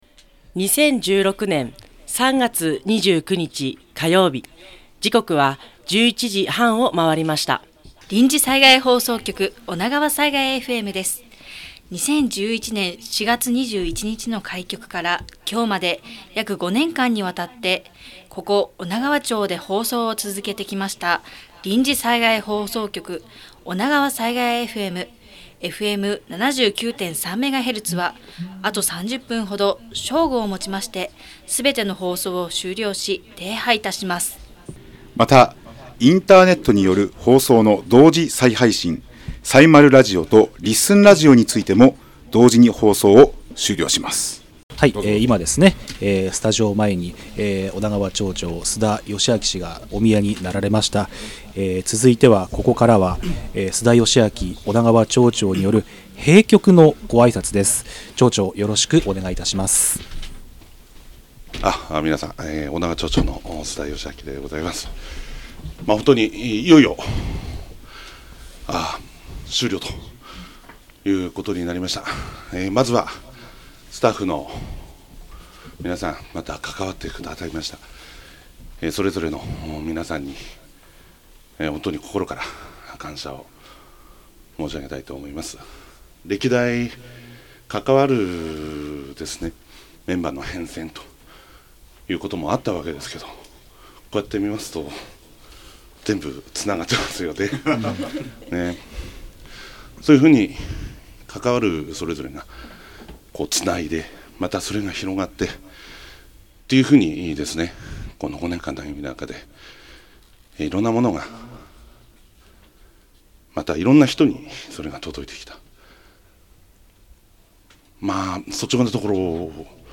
こちらは、当日放送した音源から音楽を抜いた音源となります。
最後１分間、スタッフはスタジオの外に飛び出して集まったリスナーさんたちと停波の瞬間を迎えました。